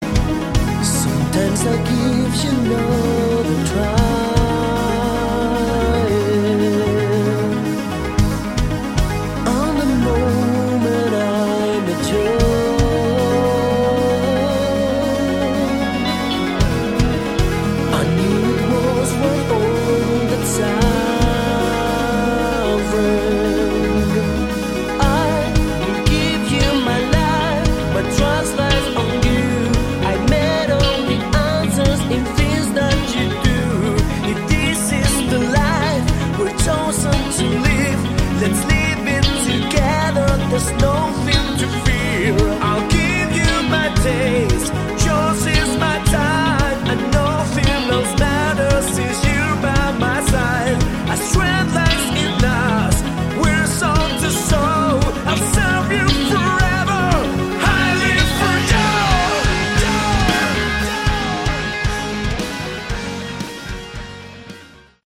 Category: Melodic Rock
drums
bass
guitars, keyboards
vocals